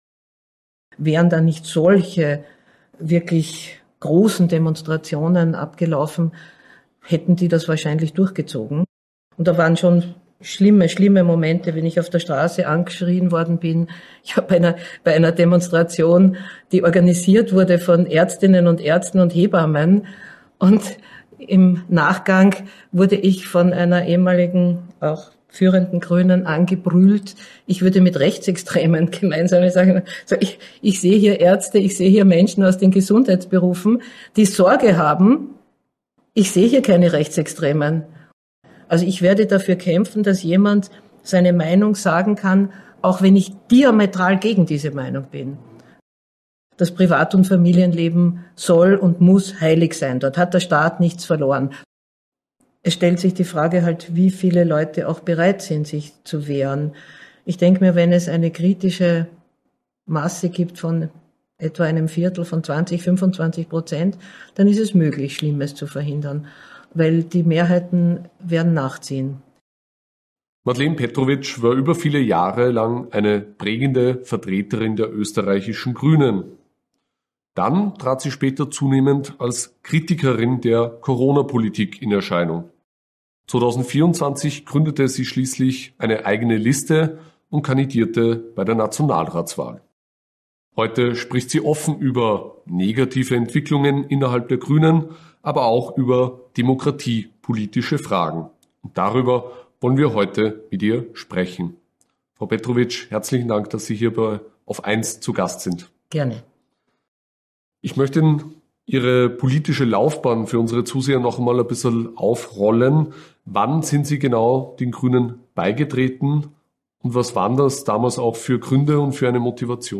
Im AUF1-Gespräch erklärt sie, wie es zum Bruch mit ihrer Partei kam. Sie spricht über innerparteilichen Druck, die Unterdrückung kritischer Stimmen – und darüber, wie der Wandel der Grünen sinnbildlich für die Entwicklung des gesamten politischen Systems steht.